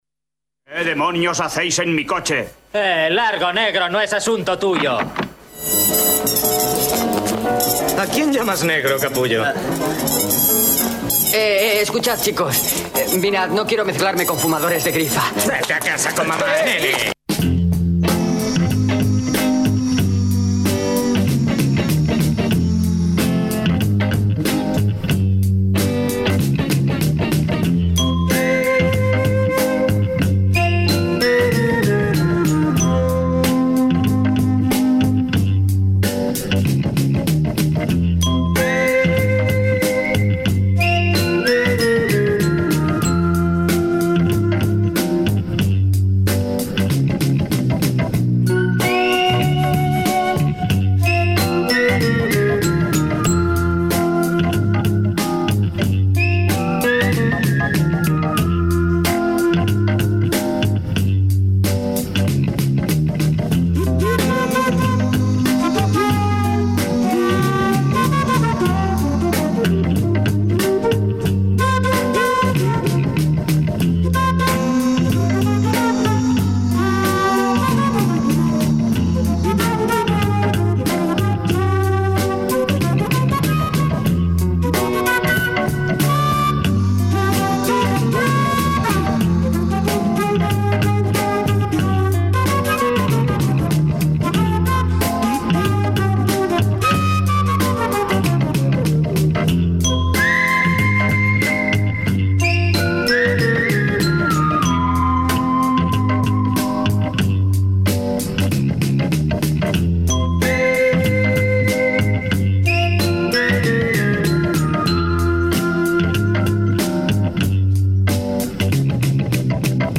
Abesti aukeraketa fina, arraroa, eta bitxia. Betiko moduan bidegabeki bazterturiko kantak berreskuratzen. Gora soinu ilunak, marginal bezain kuttunak!